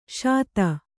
♪ śata